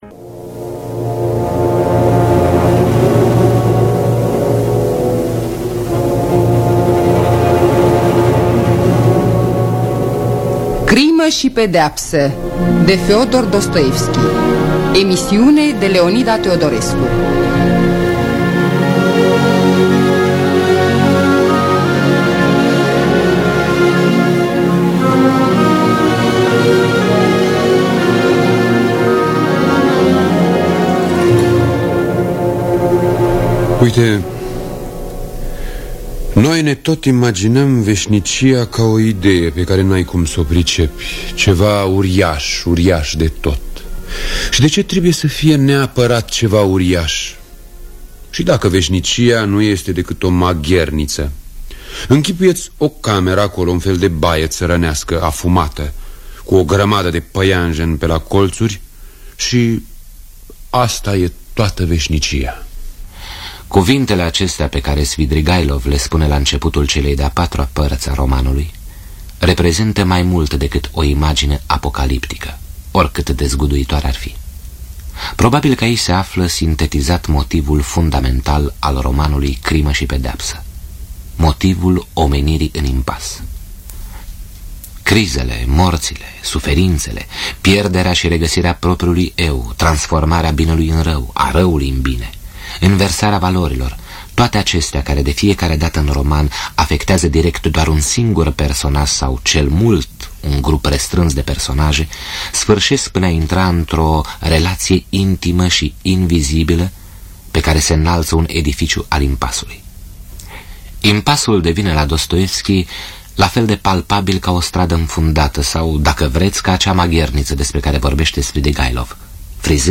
Scenariu radiofonic de Leonida Teodorescu.